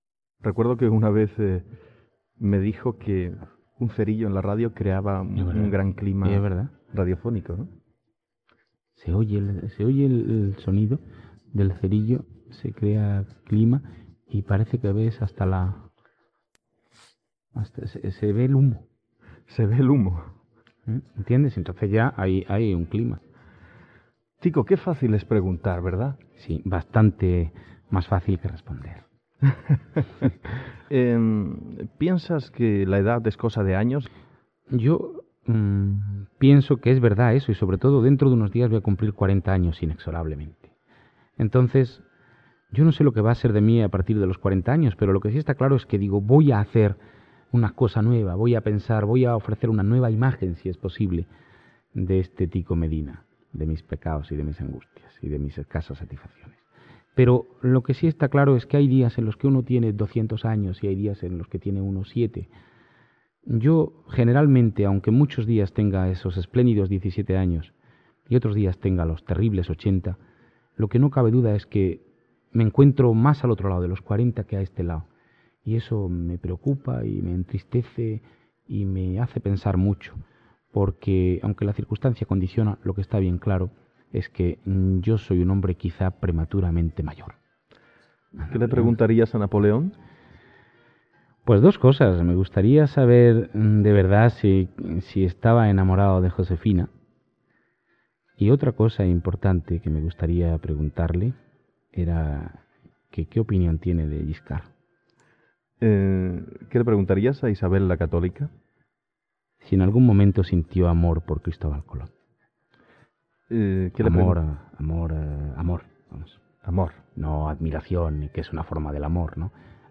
Entrevista al periodista Tico Medina